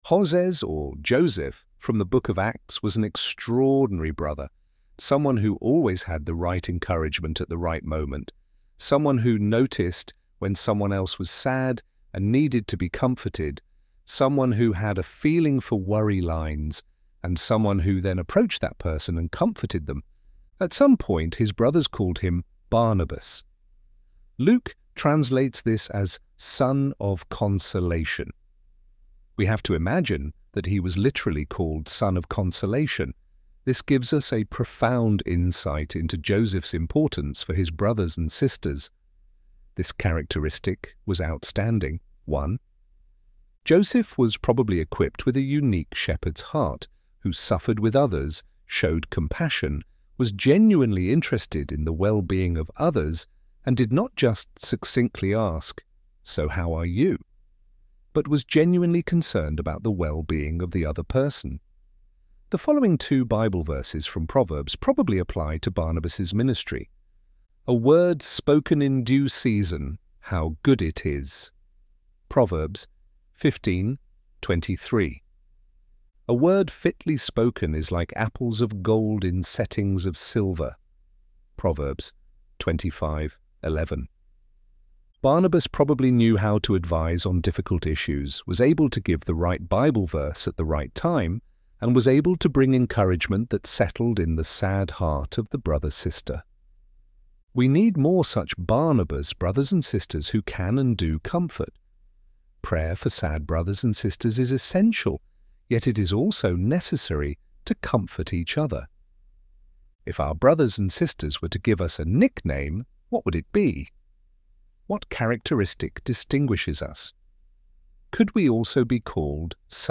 ElevenLabs_Wanted_Comforters.mp3